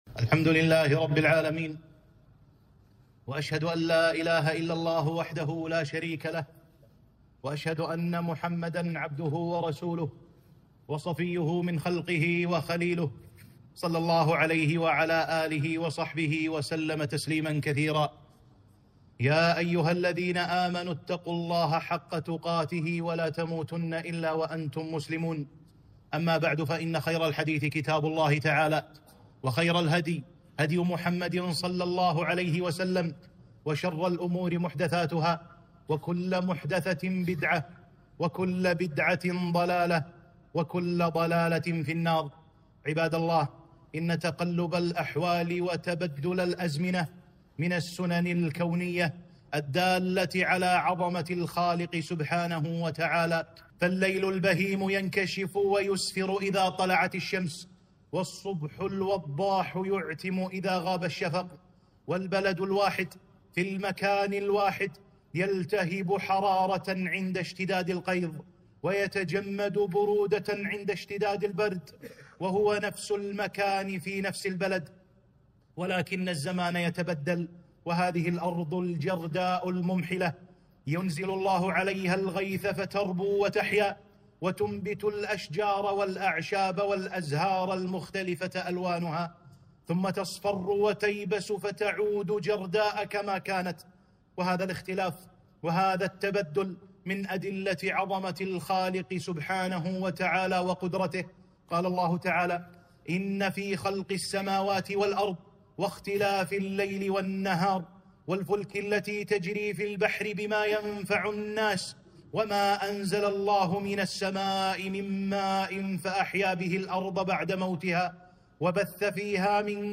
خطبة - الشتاء والبرد، آداب وأحكام